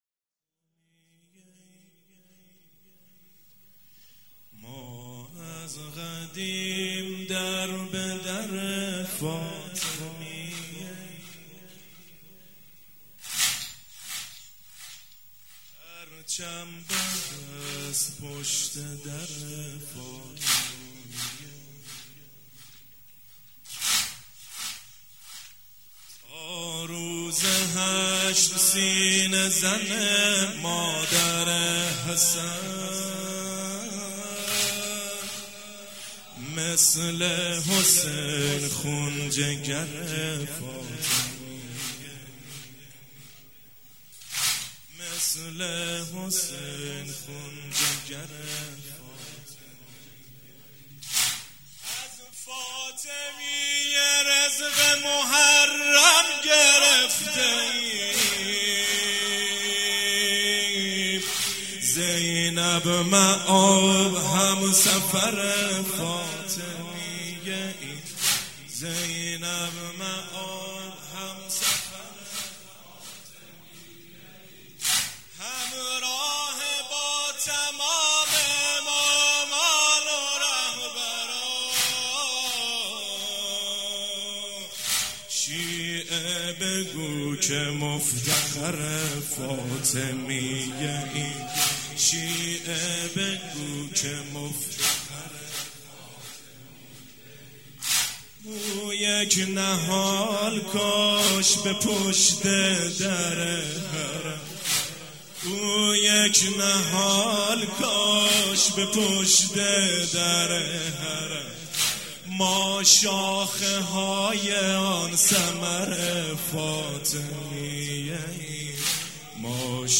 مراسم شب اول دهه فاطمیه دوم
مراسم عزاداری شب اول